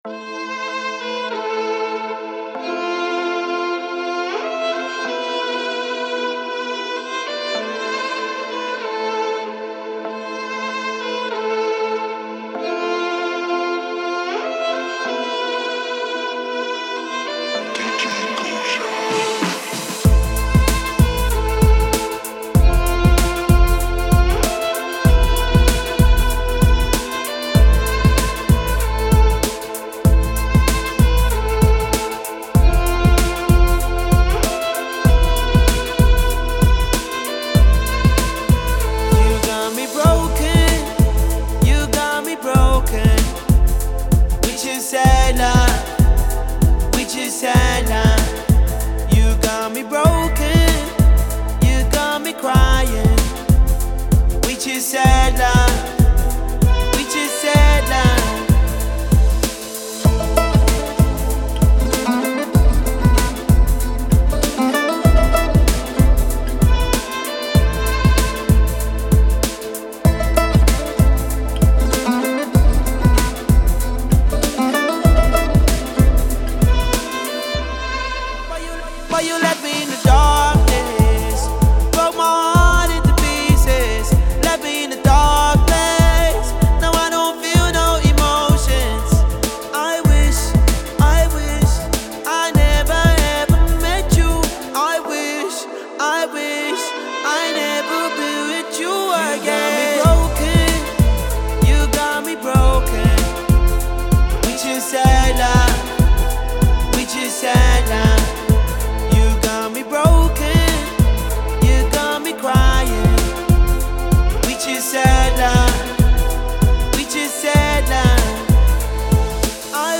Спокойная музыка
восточная музыка , спокойная музыка